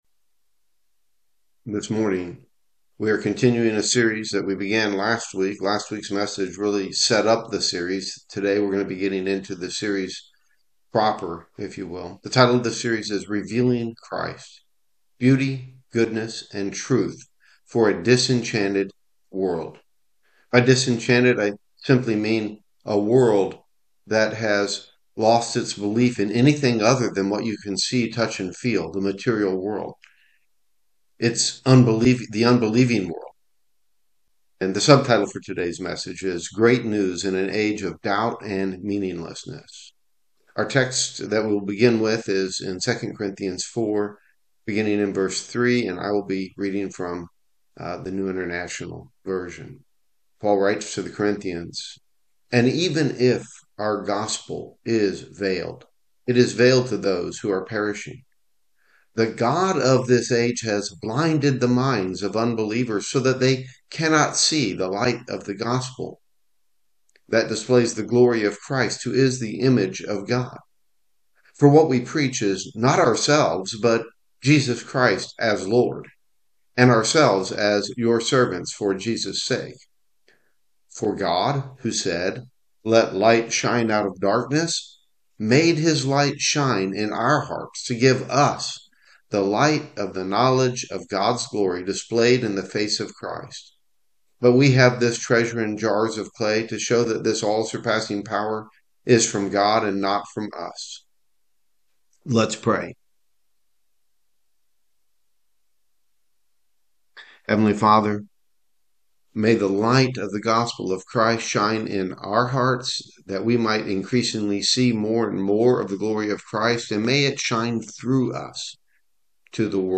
1-11-26_sermon_edit_audio.m4a